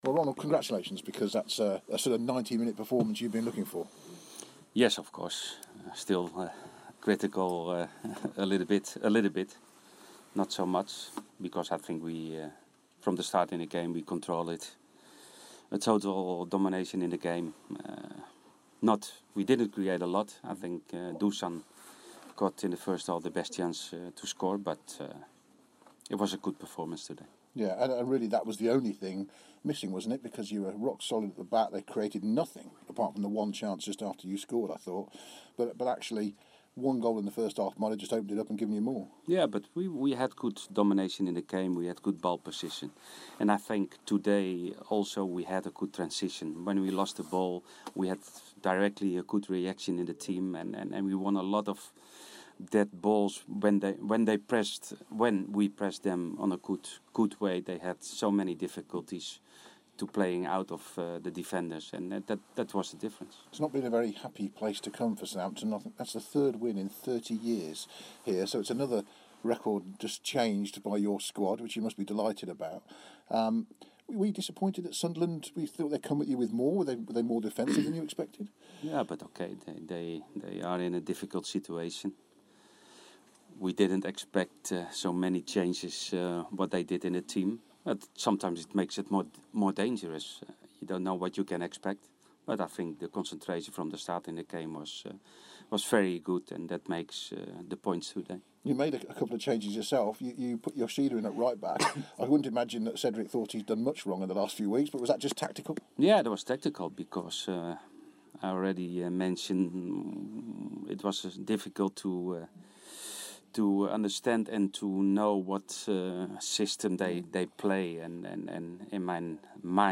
REACTION